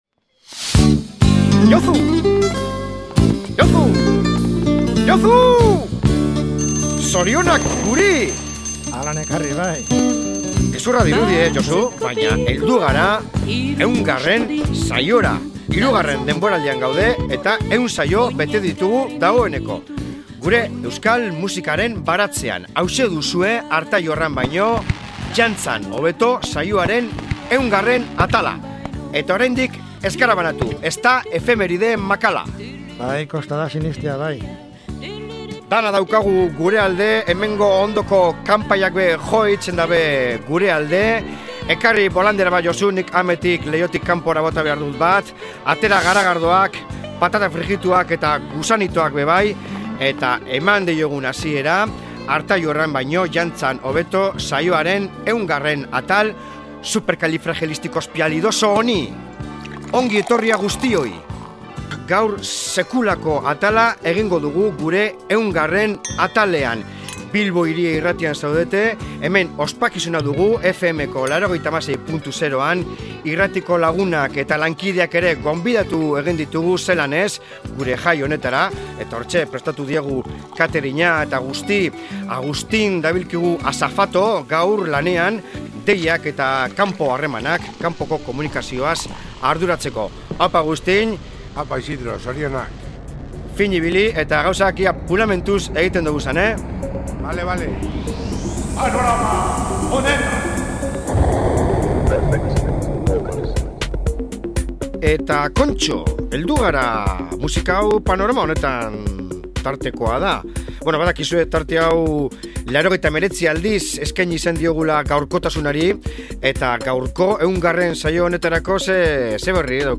Opari akustikoak!